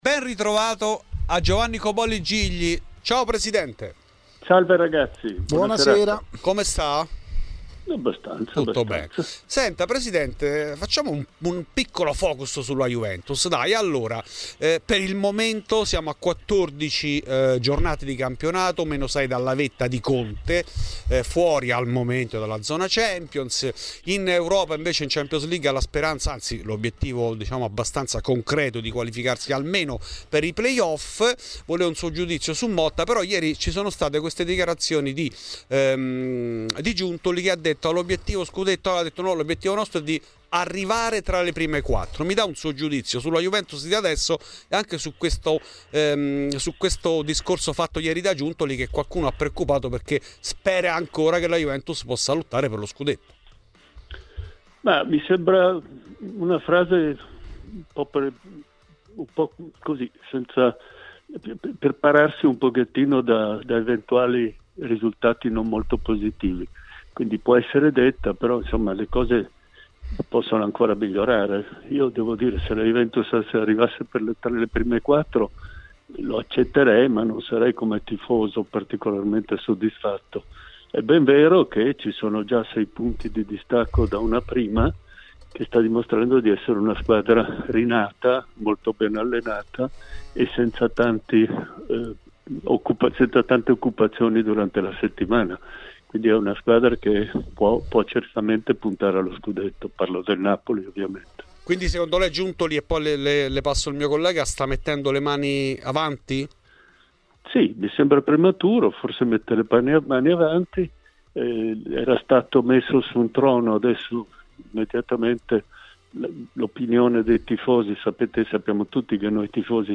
In ESCLUSIVA a Fuori di Juve è intervenuto Giovanni Cobolli Gigli. L'ex presidente della Vecchia Signora ha spiegato anche perché la società non andò avanti nel ricorso contro la sentenza di Calciopoli e i motivi dell'addio di Deschamps che lasciò la panchina a due giornate dal termine del campionato di Serie B, con la promozione assicurata.